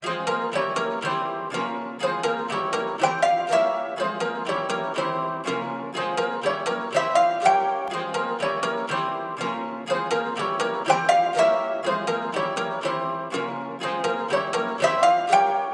标签： 122 bpm RnB Loops Guitar Acoustic Loops 2.65 MB wav Key : G
声道立体声